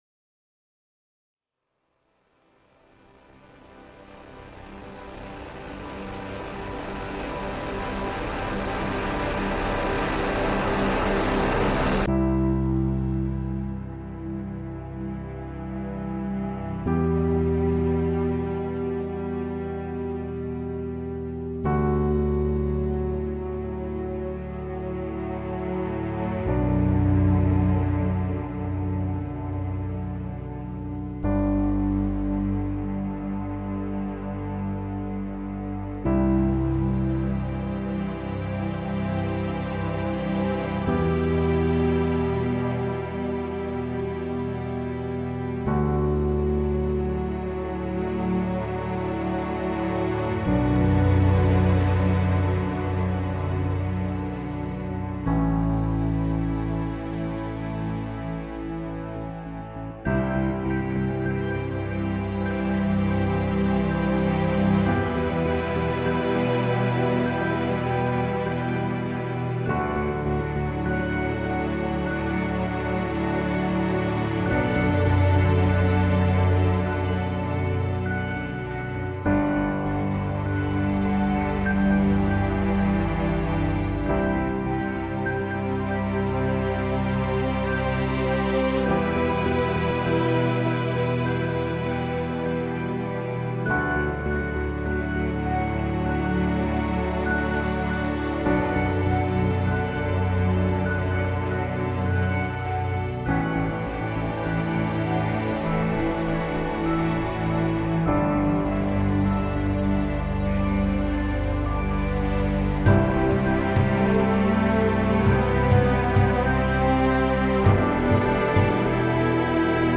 это атмосферная композиция в жанре неоклассической музыки